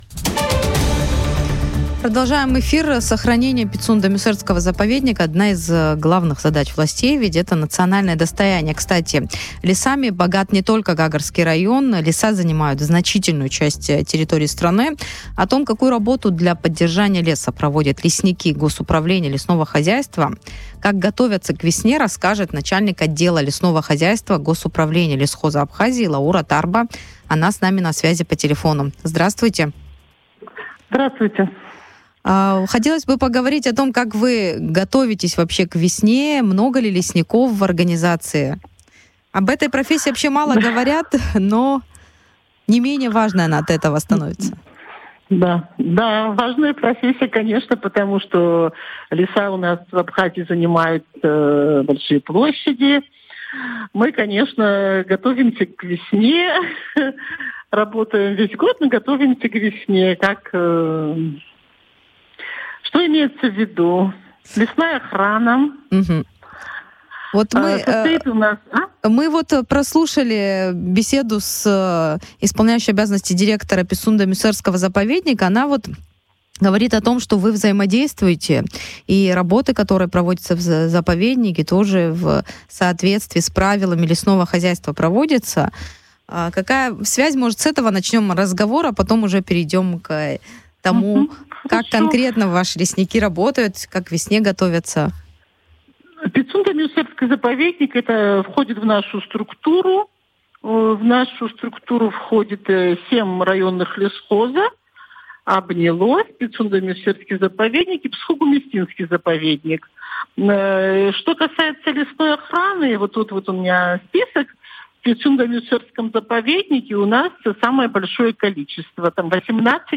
в интервью радио Sputnik